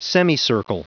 Prononciation du mot semicircle en anglais (fichier audio)
Prononciation du mot : semicircle